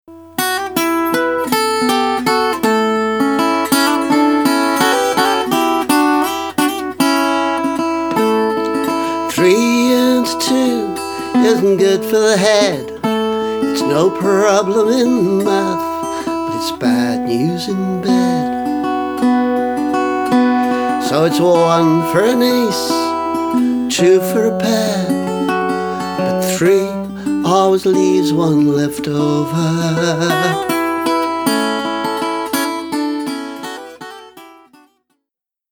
Two is a Silence – extract with just Nashville-strung guitar